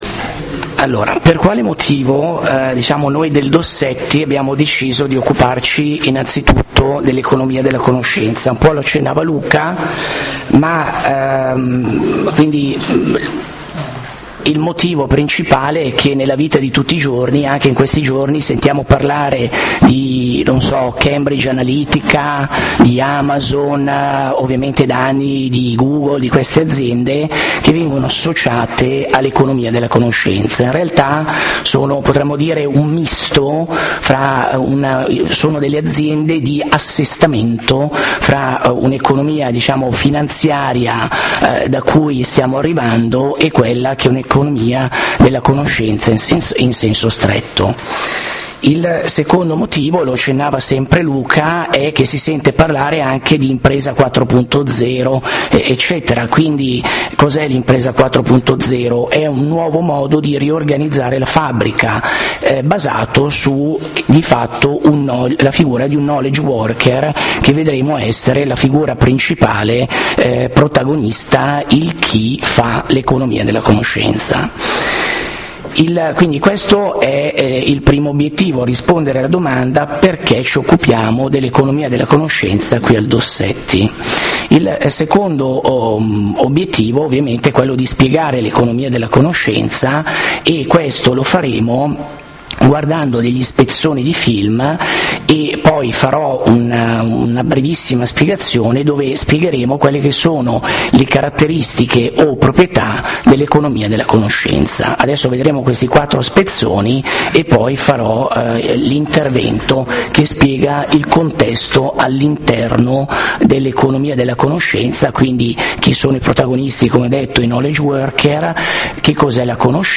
Clicca sui file audio sottostanti per ascoltare le registrazioni della lezione (se vuoi scaricare i file sul tuo computer trovi i link in fondo alla pagina)